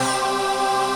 VOICEPAD14-LR.wav